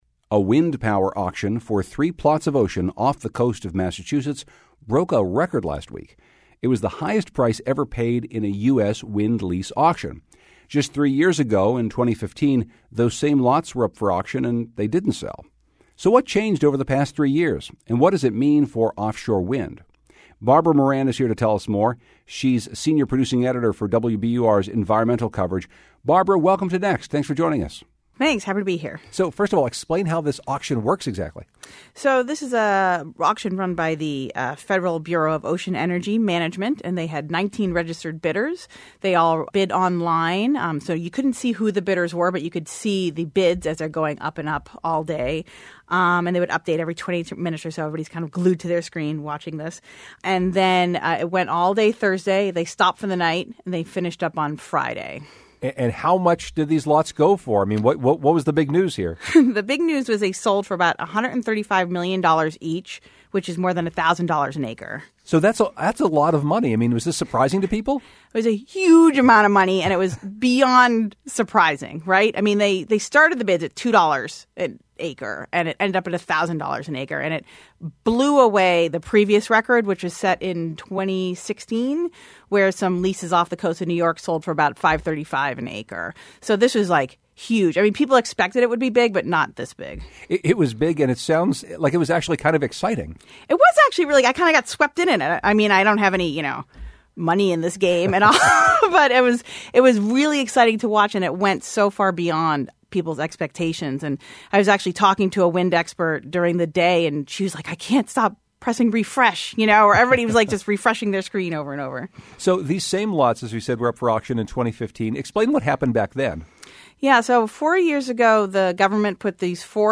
Interview Highlights